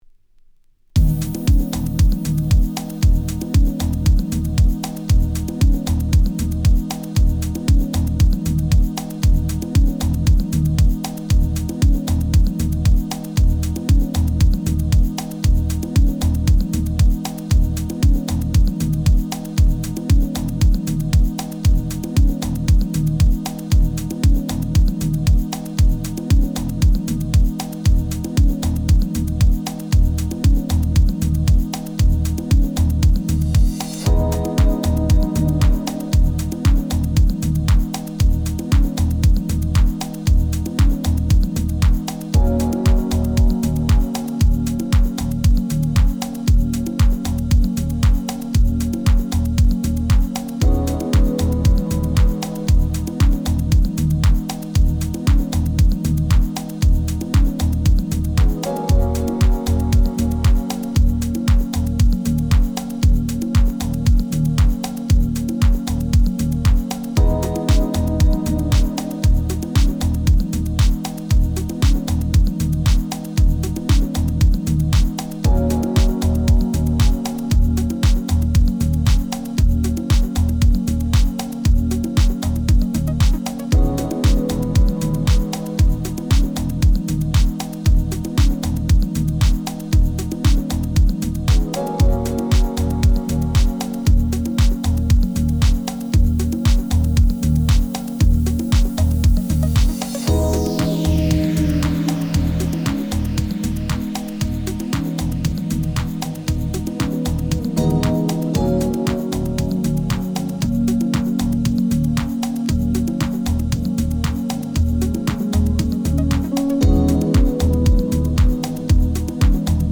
Genre: Deep House.